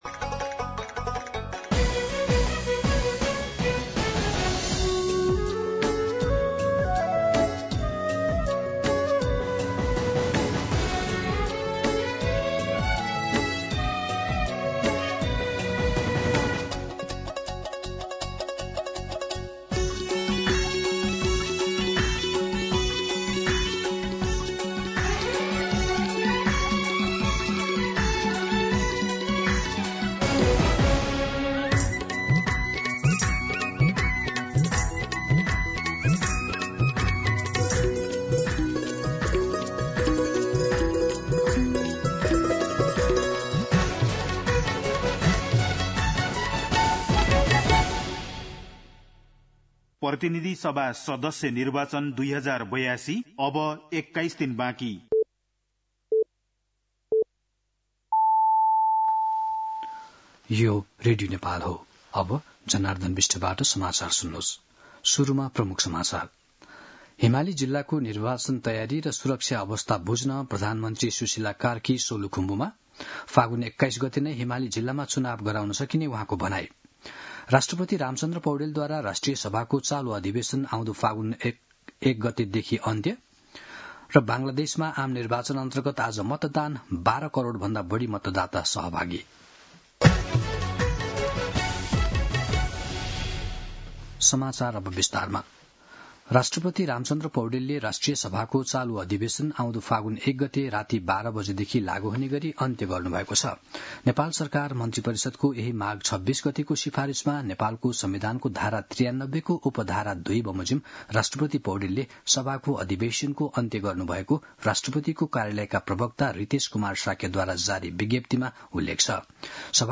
दिउँसो ३ बजेको नेपाली समाचार : २९ माघ , २०८२
3-pm-News-10-29.mp3